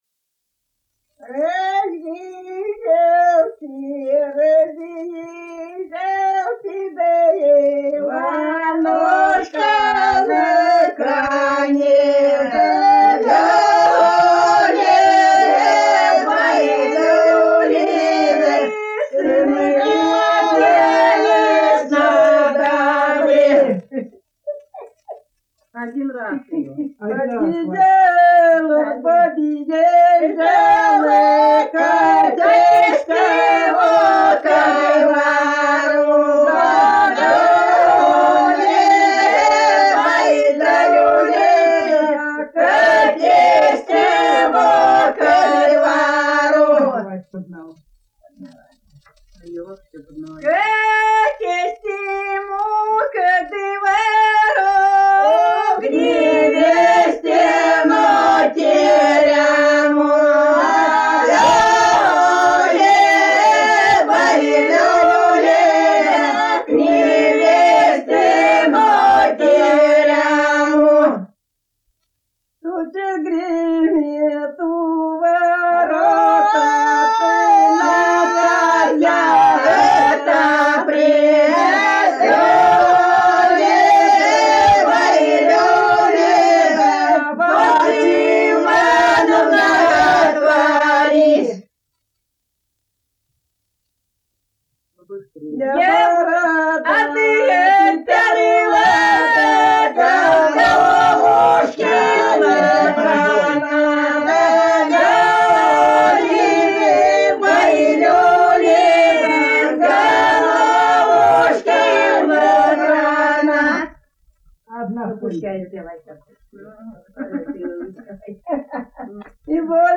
06_-_06_Разъезжалси_Иванушка_на_коне,_свадебная.mp3